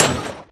Sound / Minecraft / mob / blaze / hit3.ogg
hit3.ogg